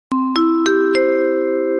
call_tone.mp3